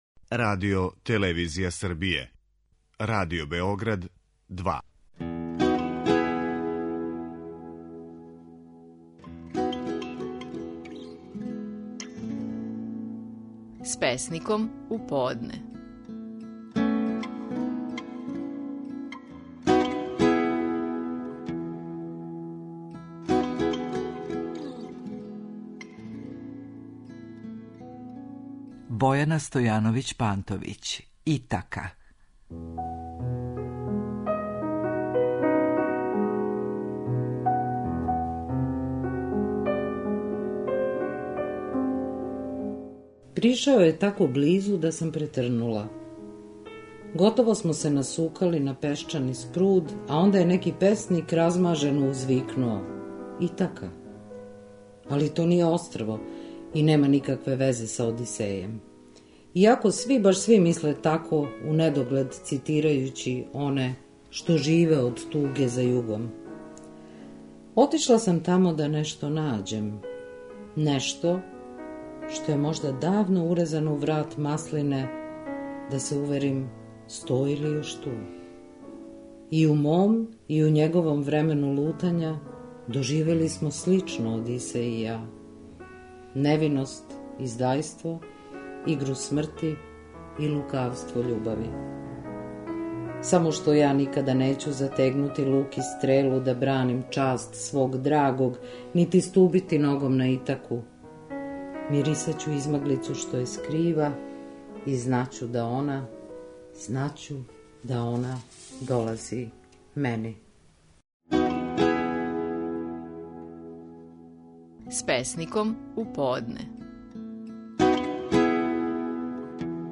Стихови наших најпознатијих песника, у интерпретацији аутора